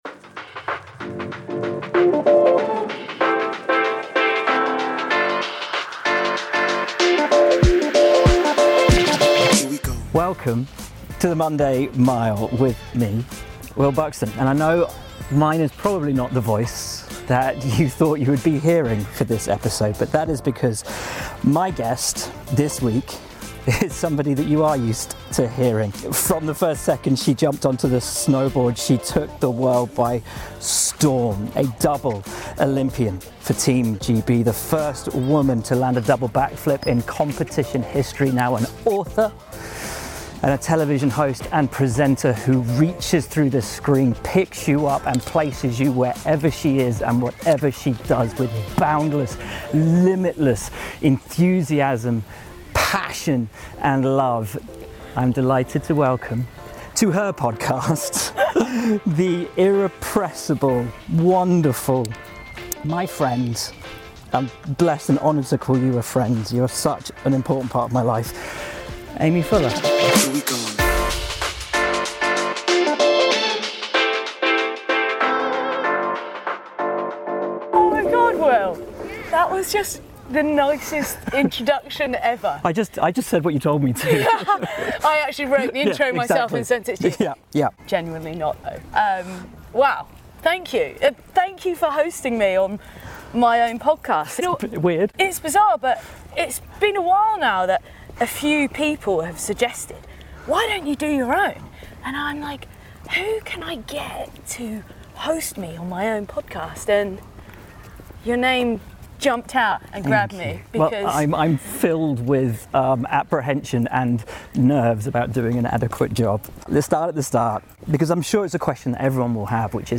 This time it's a role reversal and Will Buxton takes host seat in this one off episode.
Aimee will meet each of her guests for a walk and a chat and over the course of the mile she will find out how they cope on a Monday morning - their idiosyncrasies, breakfast routines, alarm noises - and how, when they need to, find the motivation to kick-on through the week.